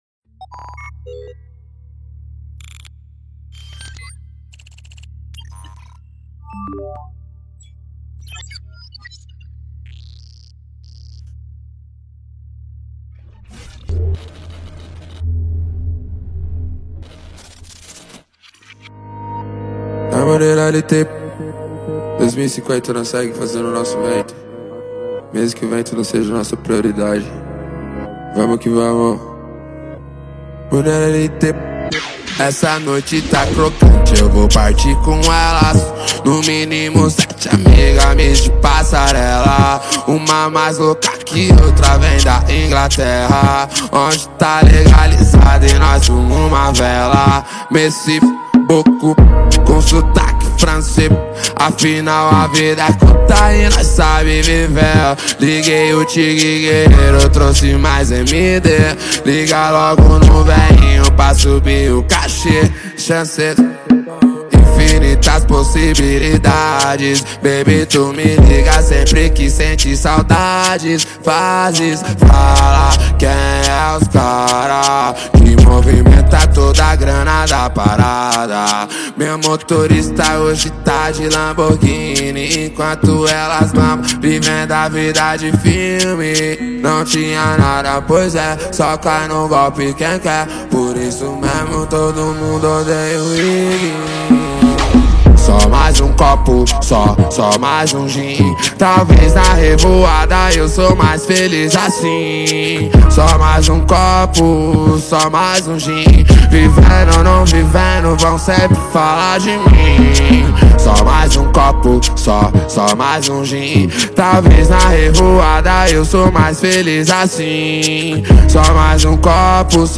2024-05-19 17:45:52 Gênero: MPB Views